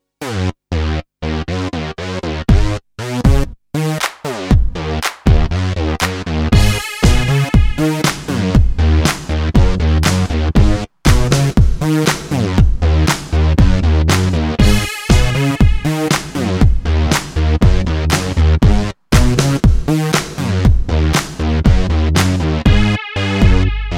Acoustic Version Pop (2010s) 3:23 Buy £1.50